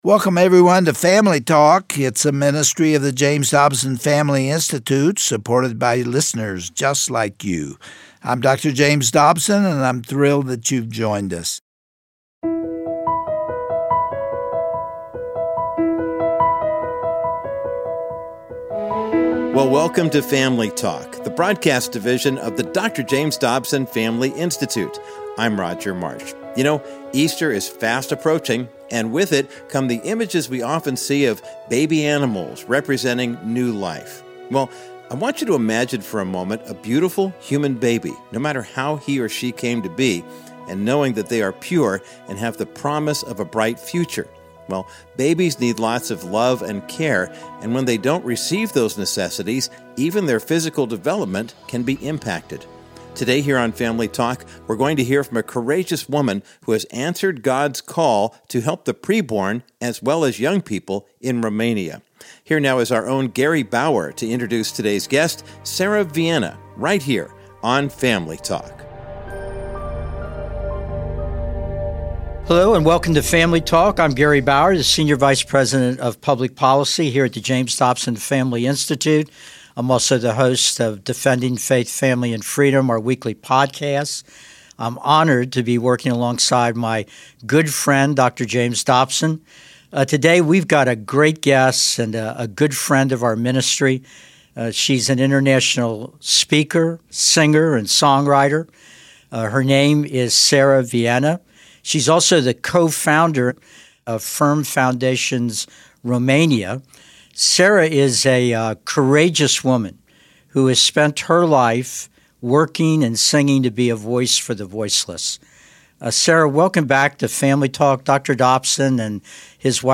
Host Gary Bauer